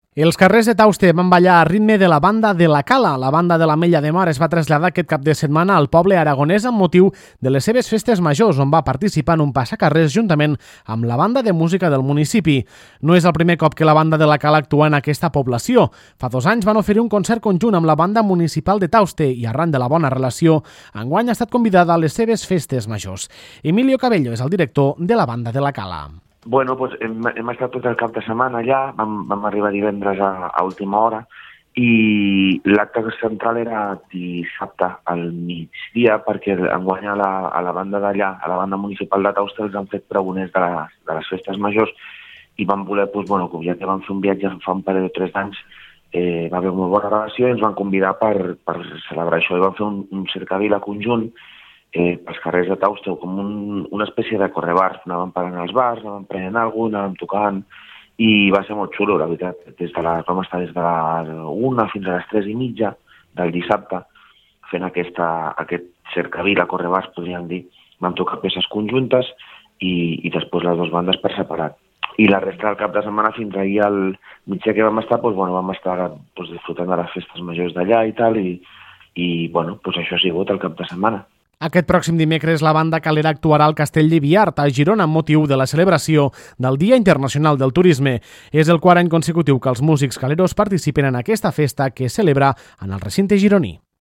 La banda de l’Ametlla de Mar va participar en les festes majors d’aquesta població aragonesa, tocant en un passacarrers juntament amb la banda de música del municipi.
La banda de l’Ametlla de Mar es va traslladar aquest cap de setmana al poble aragonès de Tauste, amb motiu de les seves Festes Majors, on va participar en un passacarrers juntament amb la banda de música del municipi.